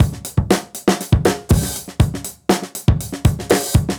Index of /musicradar/dusty-funk-samples/Beats/120bpm
DF_BeatD_120-02.wav